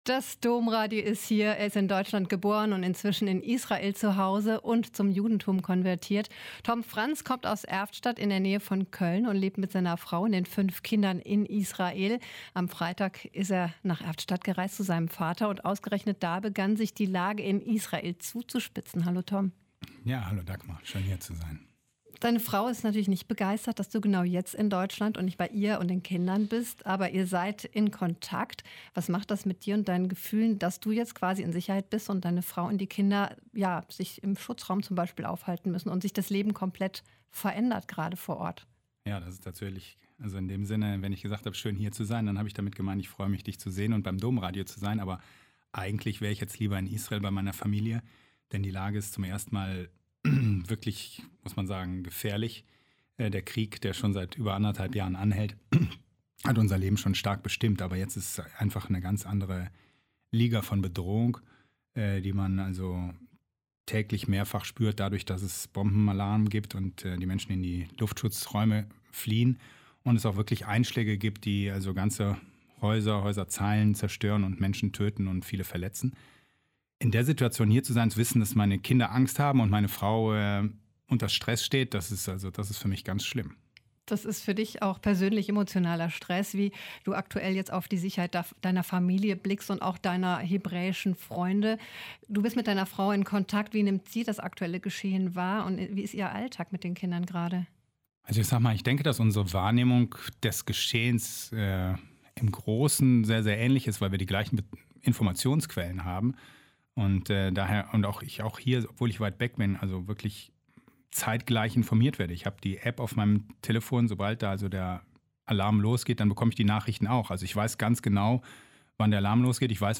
Ein Interview mit Tom Franz (Rechtsanwalt, Buchautor und Sieger der israelischen Kochshow "Masterchef")